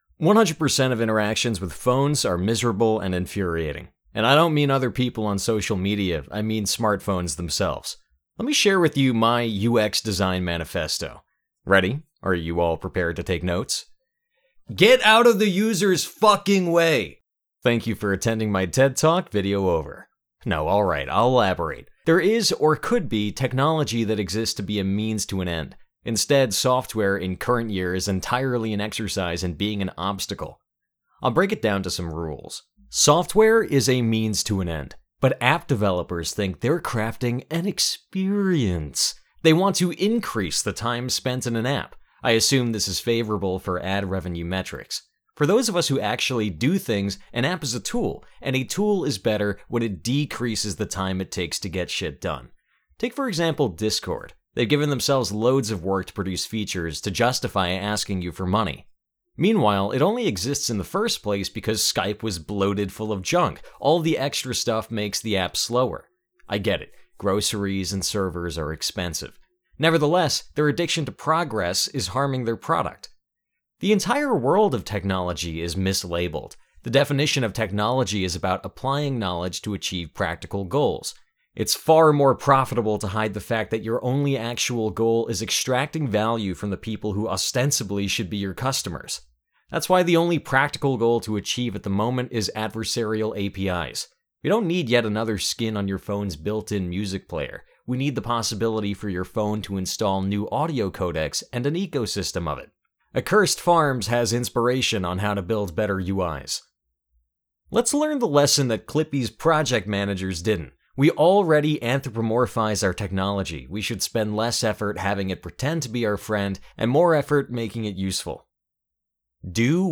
UX Manifesto VO.wav